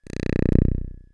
ihob/Assets/Extensions/RetroGamesSoundFX/Hum/Hum08.wav at master
Hum08.wav